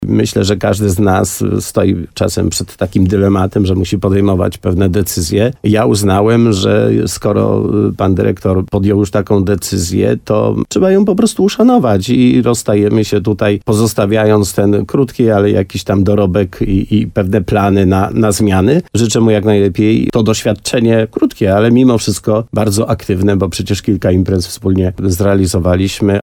Na antenie radia RDN Nowy Sącz potwierdził to wójt gminy Korzenna Leszek Skowron.
Jak powiedział w programie Słowo za Słowo, dyrektorom sam złożył rezygnację, a ostatnim dniem pracy był 31 lipca.